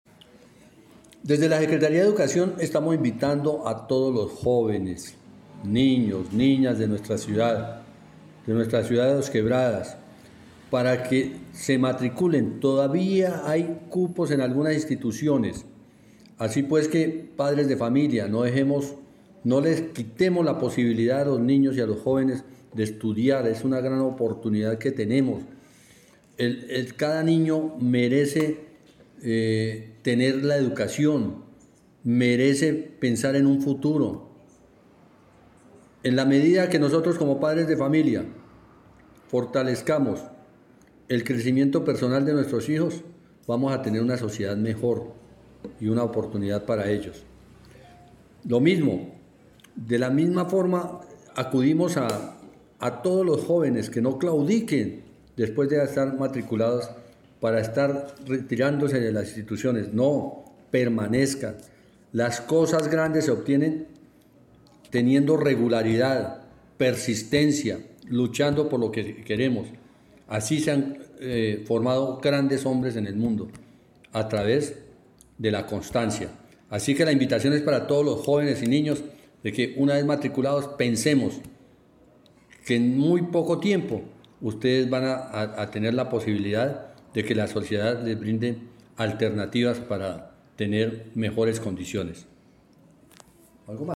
Comunicado-097-Audio-Secretario-de-Educacion-de-Dosquebradas-Celso-Omar-Parra.mp3